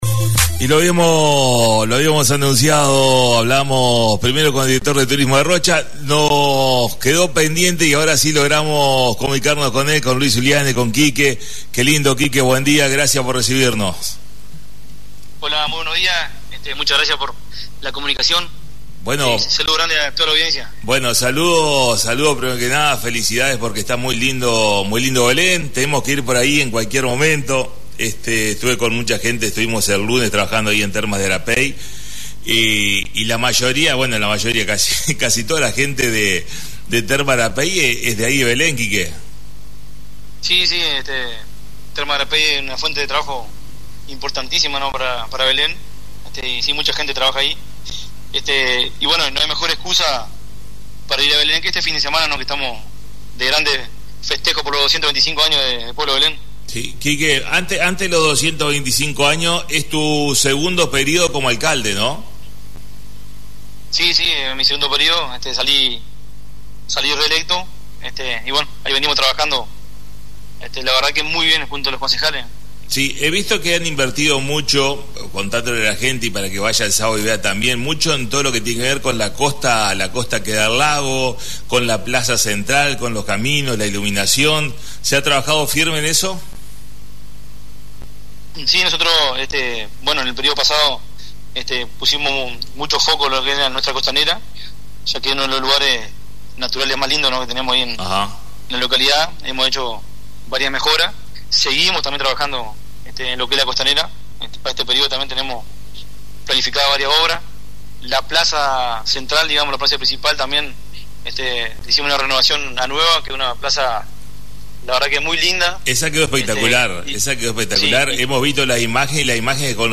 Comunicación en vivo con Luis Zulliani “Quique”, Alcalde de Pueblo Belén hablando de la actualidad del Pueblo y la gran fiesta para celebrar los 225 años el próximo sábado!